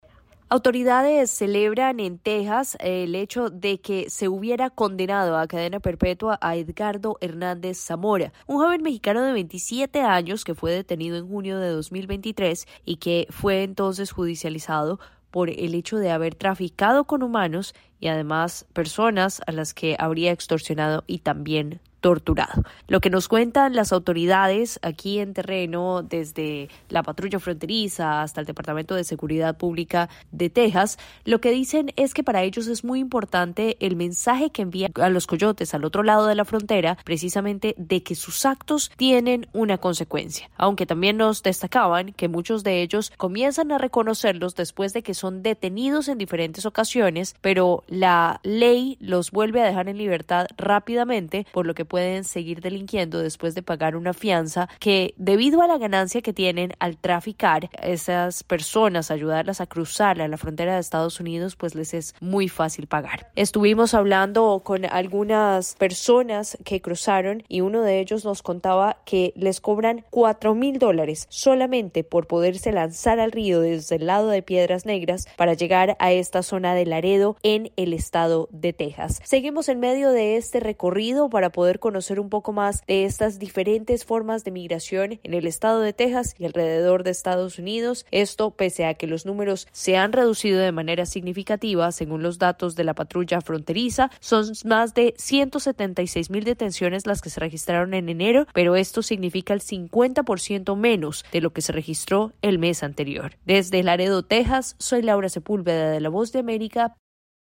este el informe desde Laredo